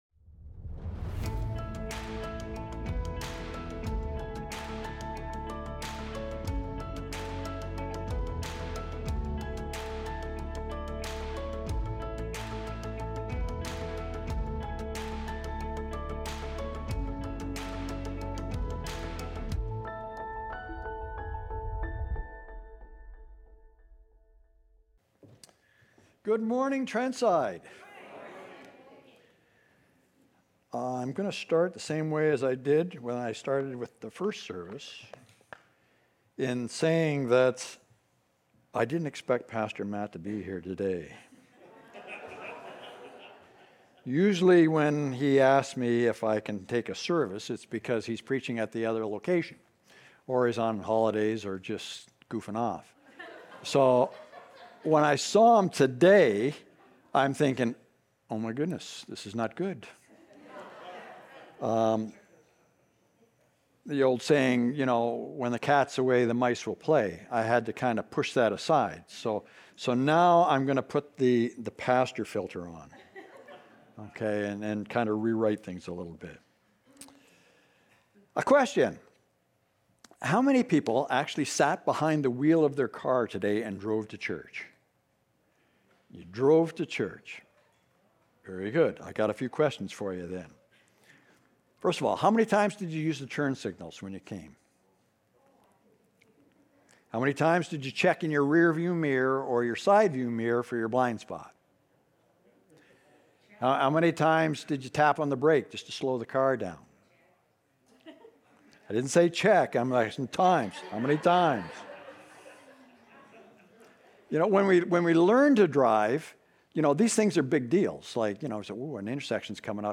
Recorded Sunday, August 24, 2025, at Trentside Fenelon Falls.